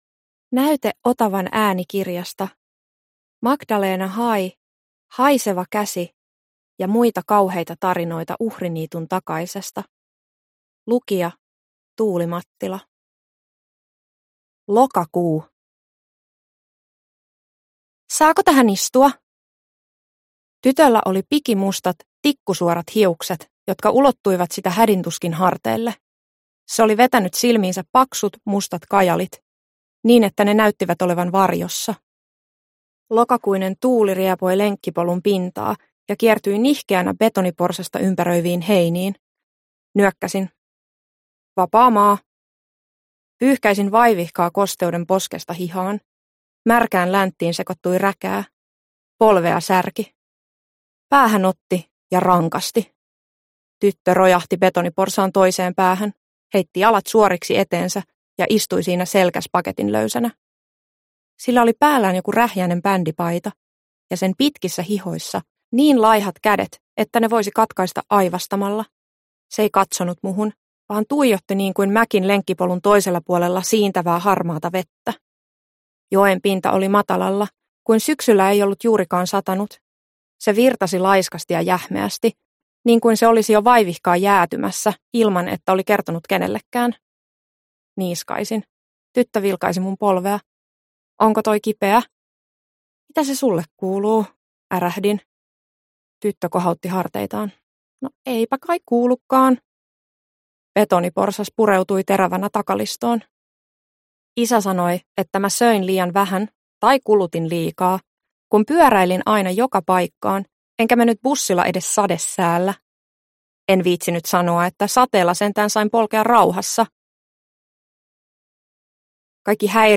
Haiseva käsi ja muita kauheita tarinoita Uhriniituntakaisesta – Ljudbok – Laddas ner